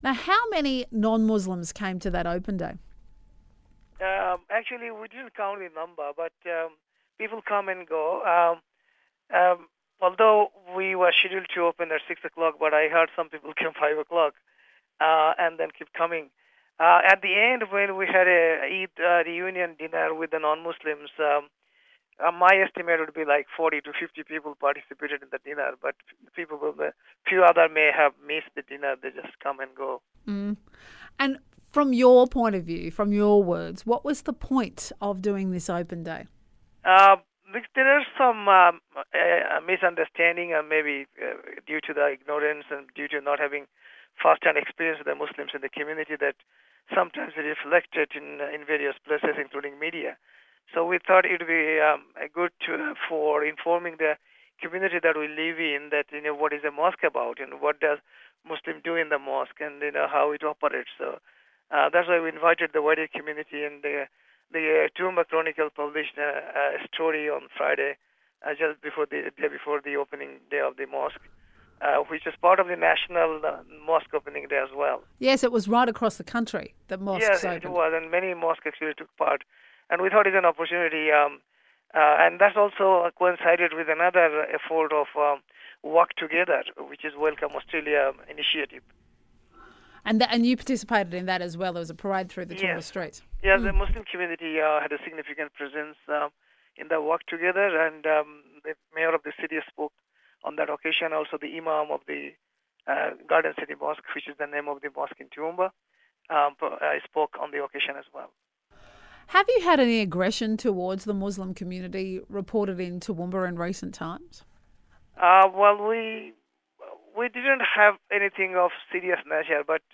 ABC Morning Radio